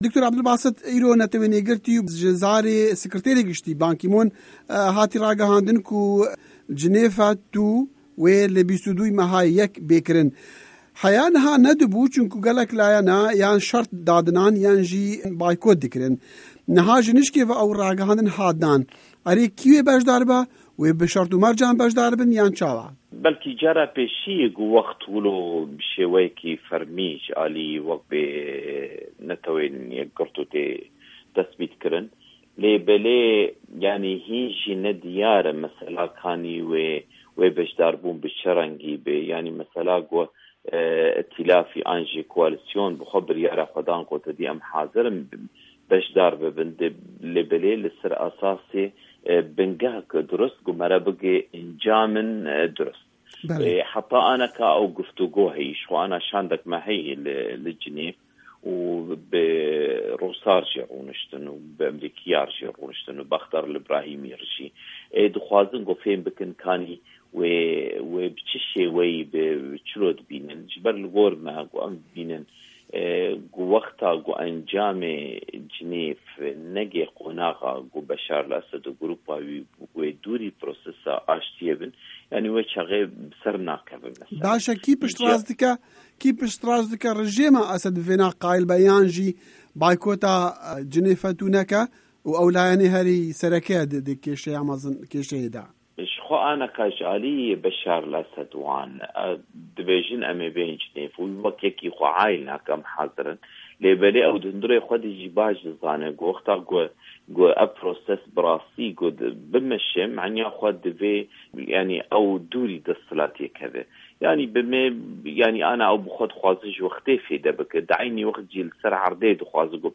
Hevpeyvîn digel Dr. Ebdulbasit Seyda